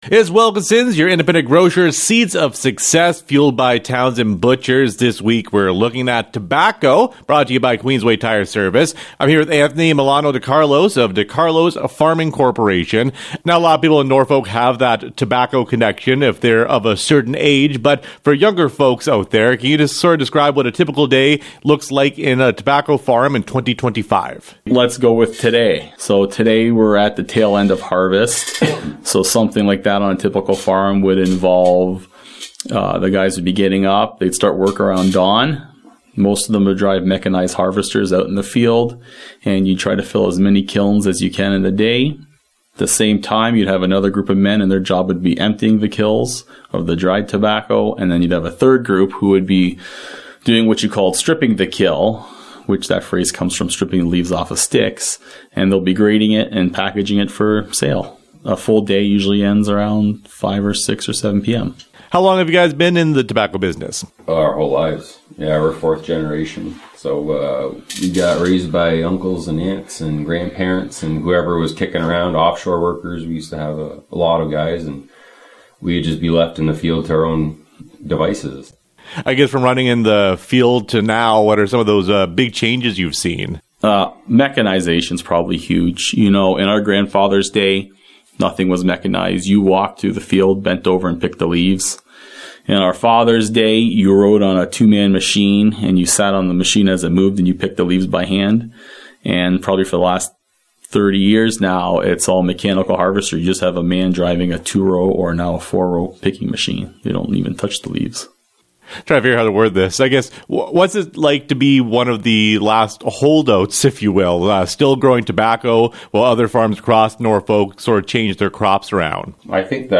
seeds-interview-2.mp3